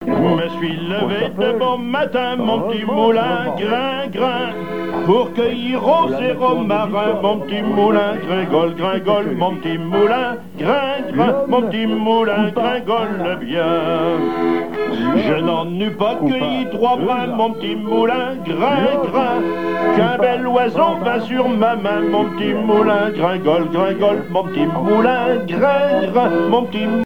Genre laisse
émission La fin de la Rabinaïe sur Alouette
Pièce musicale inédite